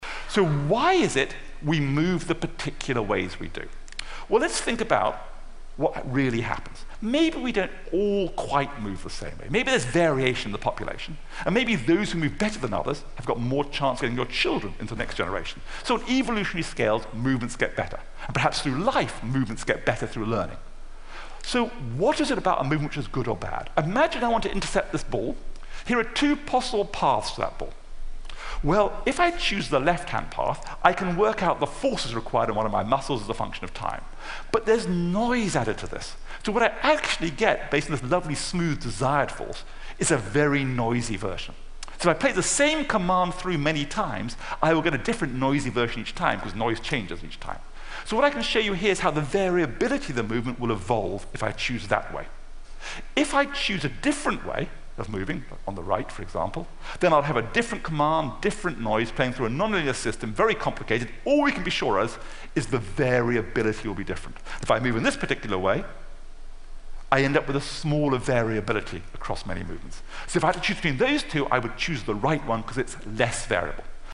TED演讲：拥有大脑的真正原因(12) 听力文件下载—在线英语听力室